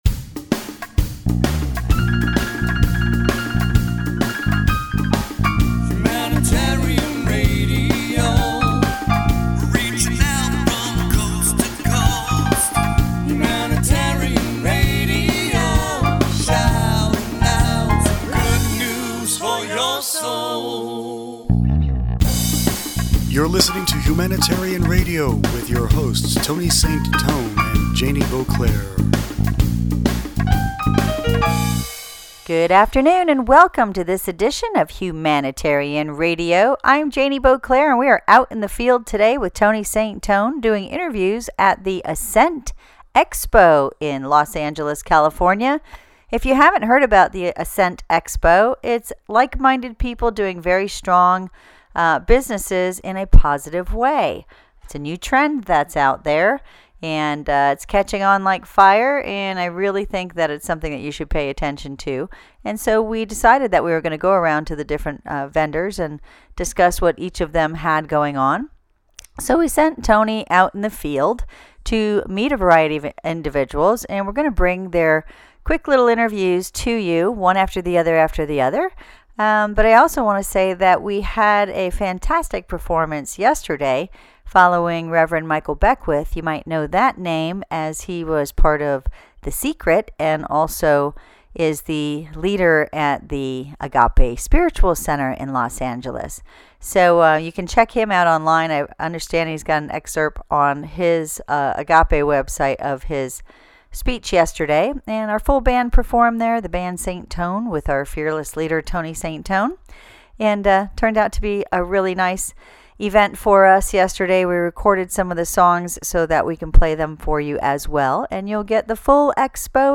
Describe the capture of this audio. Entertainment HR Live at the Ascent Expo in LA